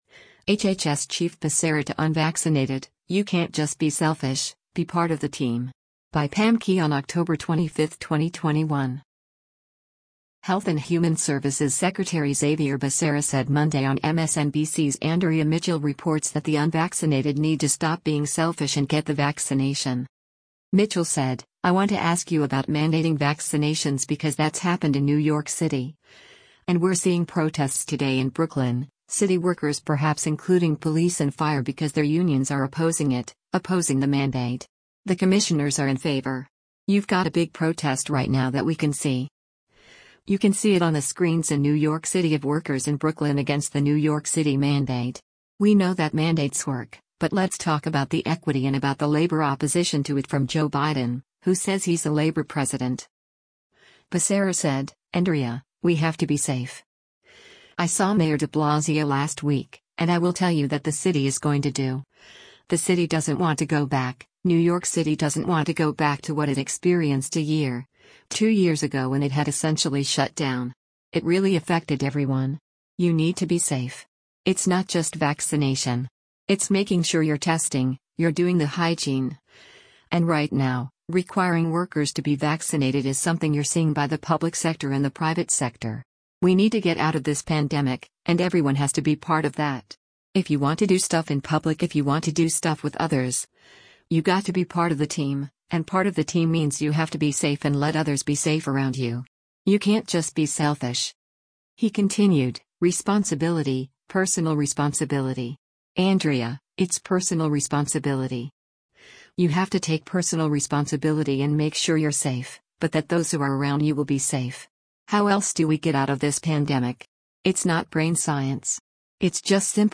Health and Human Services Secretary Xavier Becerra said Monday on MSNBC’s “Andrea Mitchell Reports” that the unvaccinated need to stop being “selfish” and get the vaccination.